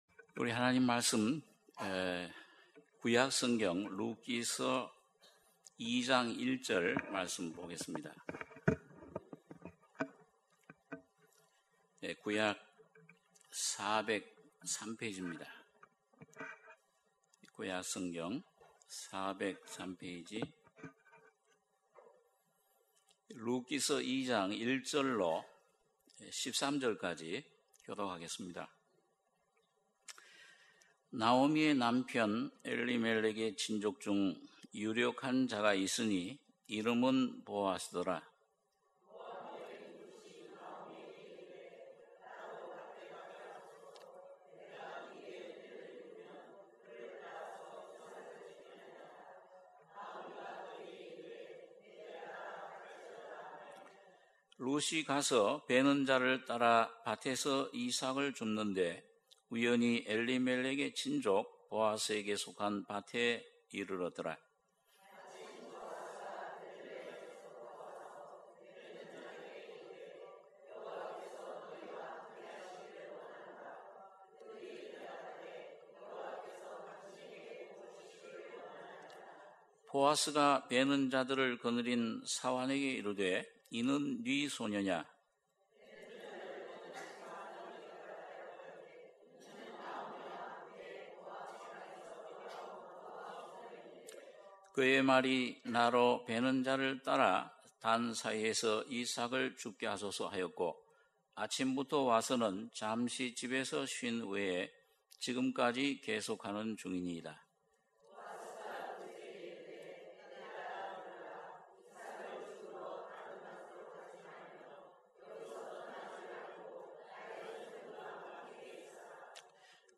주일예배 - 룻기 2장 1절~13절 주일1부